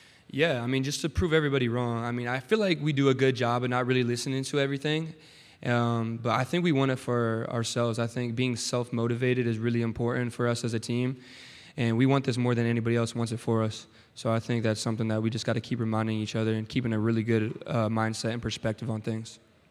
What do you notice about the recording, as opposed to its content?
Media Days are being held at the Nashville Grand Hyatt Hotel in Nashville, Tennessee.